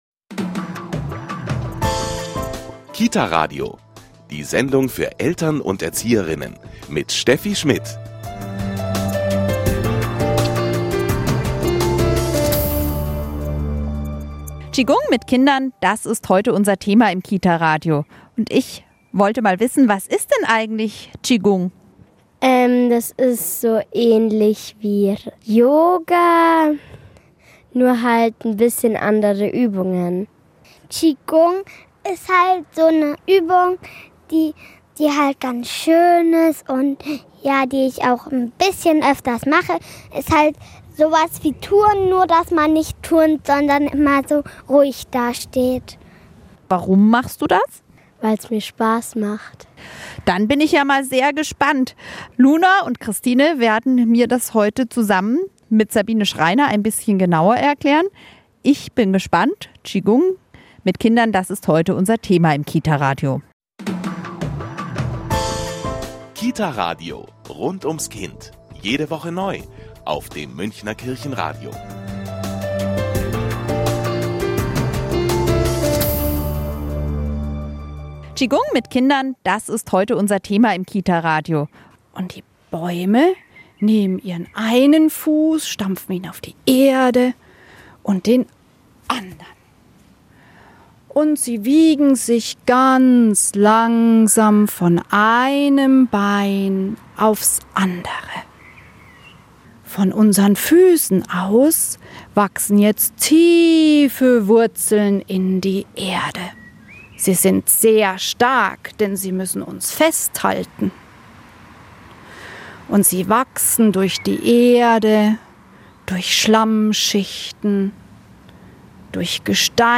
Ein Radiobeitrag über Qigong mit Kindern des Münchner Kita-Radios